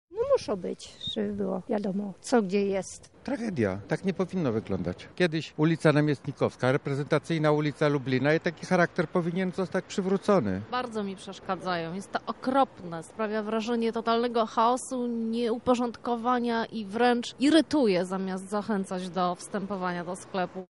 Zapytaliśmy lublinian, co sądzą na ten temat:
sonda